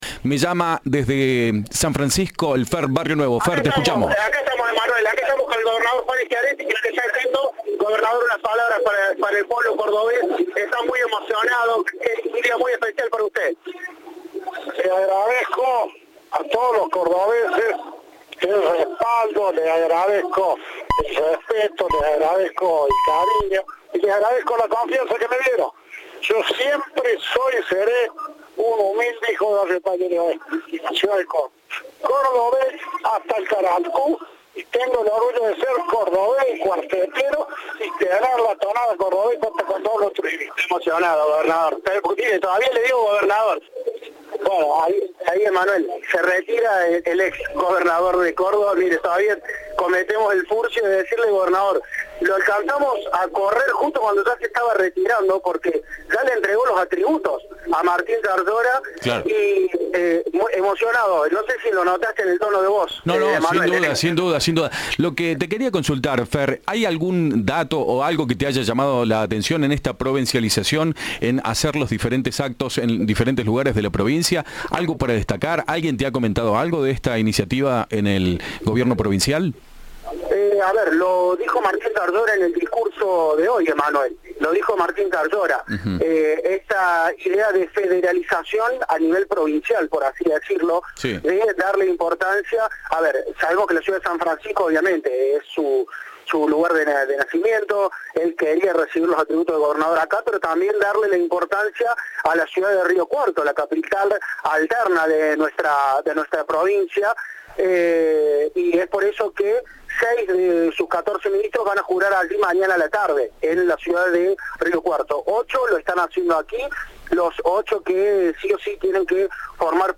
El acto se llevó a cabo en San Francisco.
“Les agradezco a todos los cordobeses el respaldo, el respeto, el cariño y la confianza que me dieron”, declaró el gobernador saliente en un dialogo exclusivo con Cadena 3.